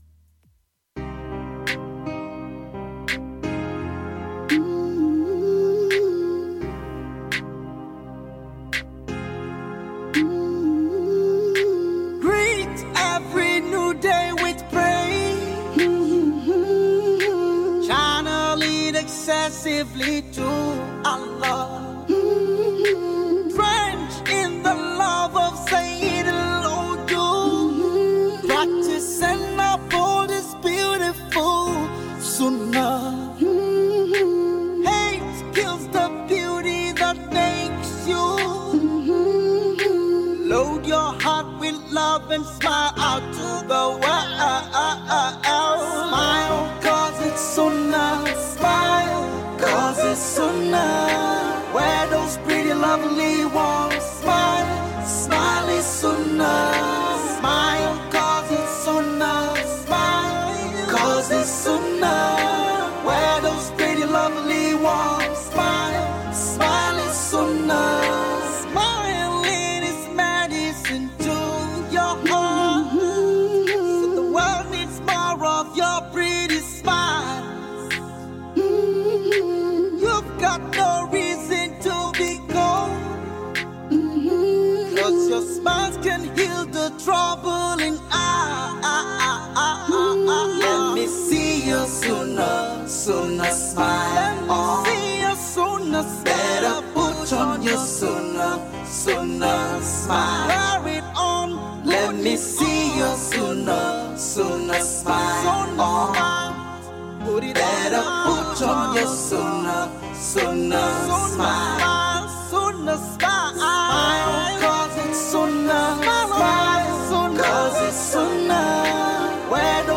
Música [Nashid]